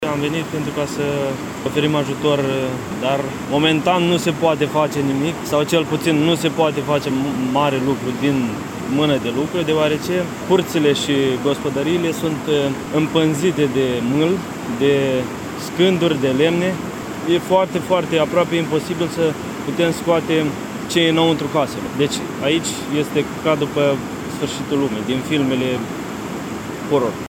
Corespondență de la fața locului